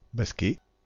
Ääntäminen
Synonyymit basque Ääntäminen Tuntematon aksentti: IPA: /bas.kɛ/ Haettu sana löytyi näillä lähdekielillä: ranska Käännöksiä ei löytynyt valitulle kohdekielelle.